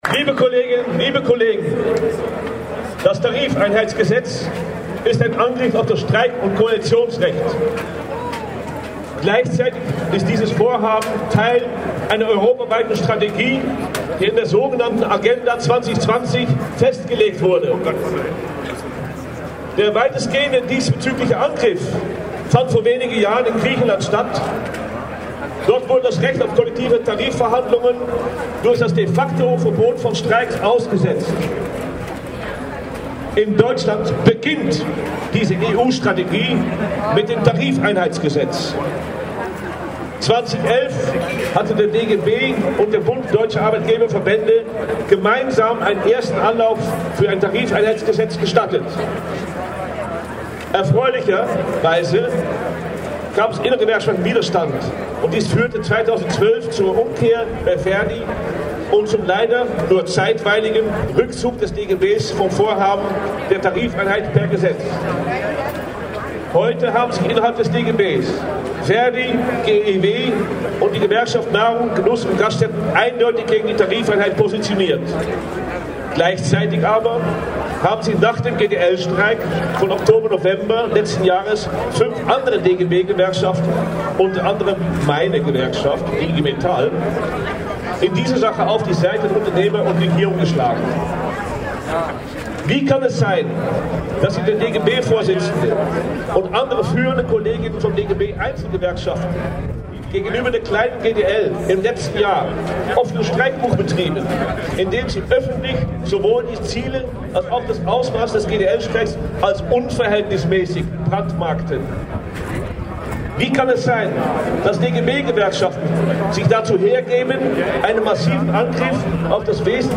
Kurzer zusammenfassender Redebeitrag am Ende der Demo: